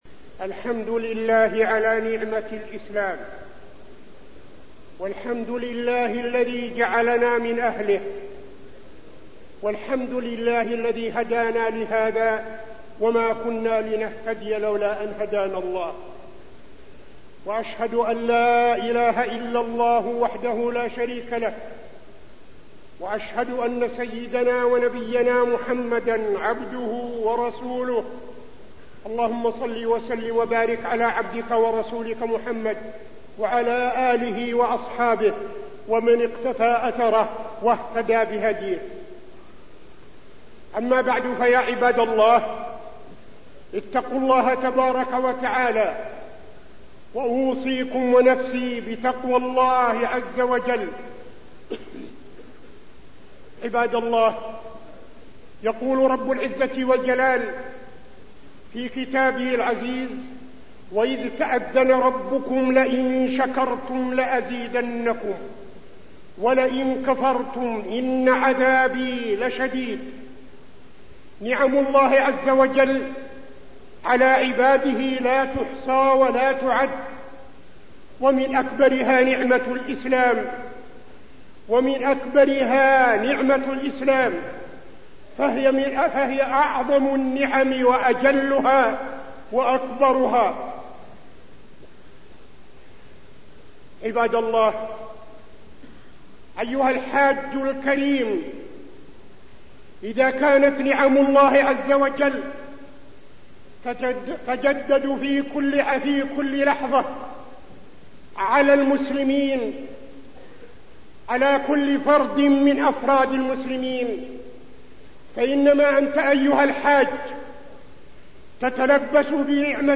خطبة نعم الله على الحجاج وفيها: أن الإسلام أفضل نعمة وأعظمها، وطاعة أوامر الله وامتثال وأوامره هو سبب للأمن والاستقرار
تاريخ النشر ١٩ ذو الحجة ١٤٠٤ المكان: المسجد النبوي الشيخ: فضيلة الشيخ عبدالعزيز بن صالح فضيلة الشيخ عبدالعزيز بن صالح نعم الله على الحجاج